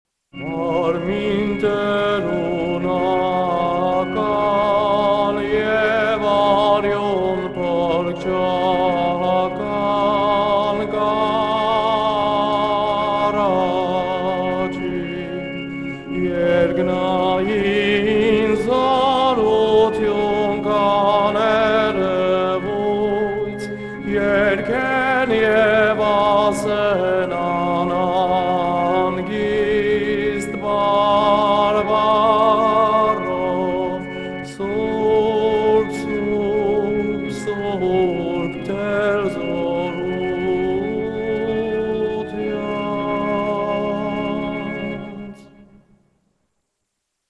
Click the video above and watch for these cues in “Marmeen Deroonagan” during Badarak at St. Sahag Mesrob Church in RI . 2) How It Sounds Press the “Show Audio Player” button above and press play to listen to the entire hymn sung beautifully and faithfully.